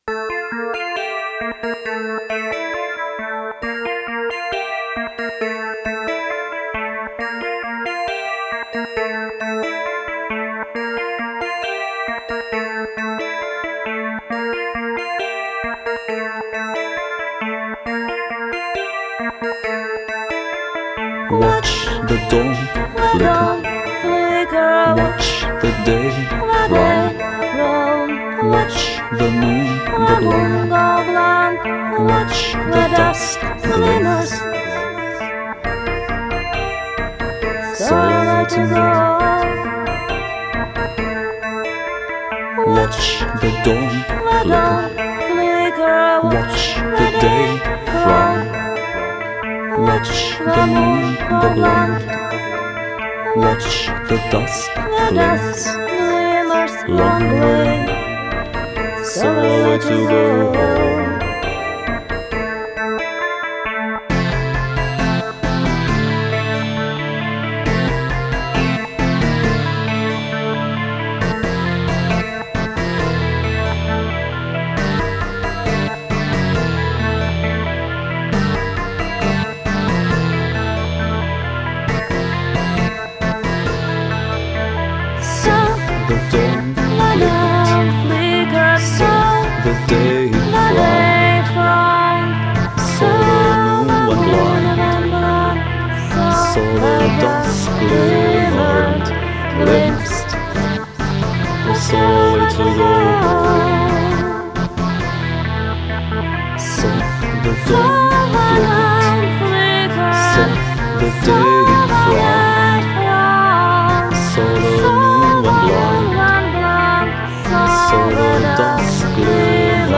вокал
гитара
синтезаторы.